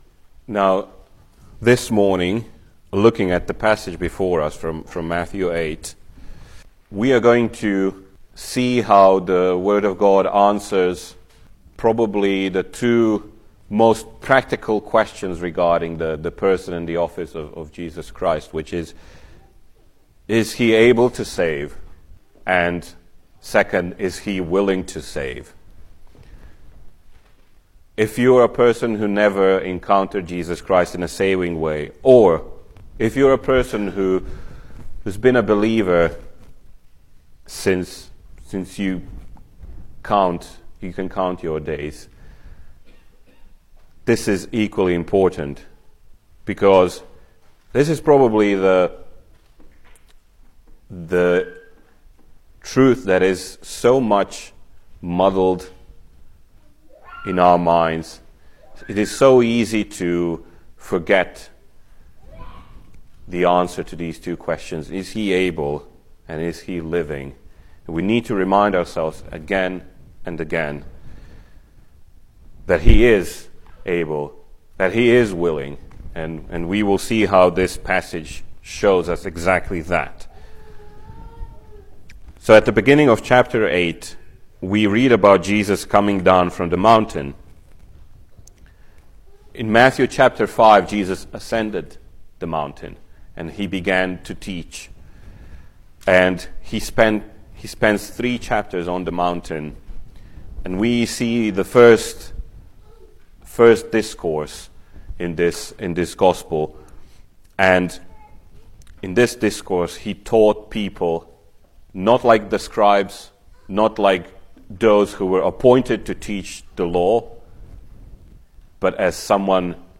Service Type: Sunday Morning
Series: Single Sermons